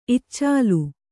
♪ iccālu